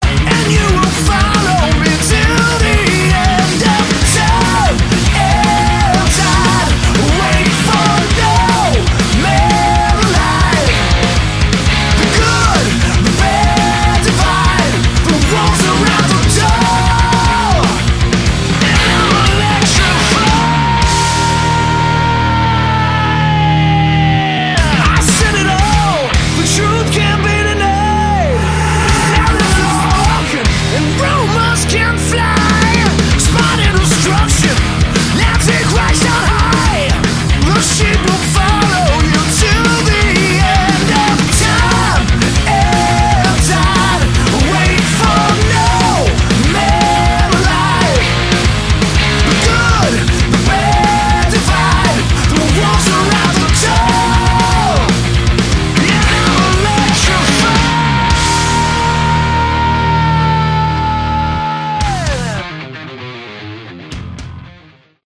Category: Hard Rock
vocals
drums
bass
guitar